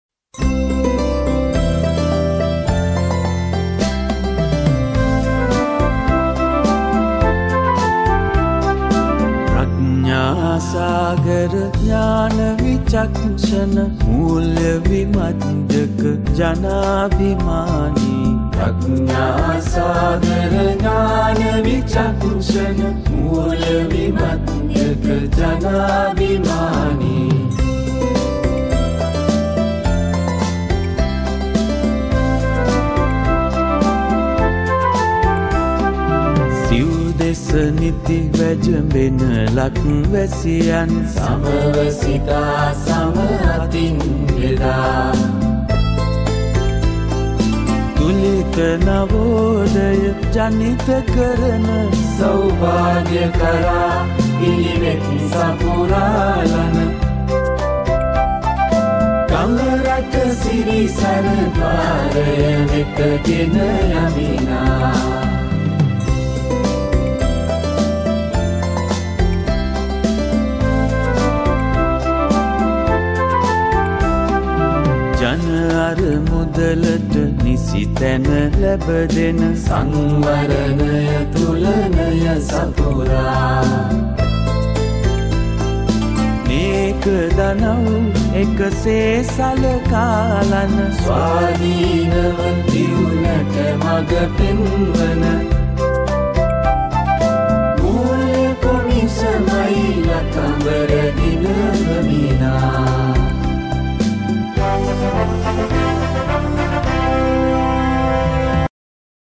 Theme Song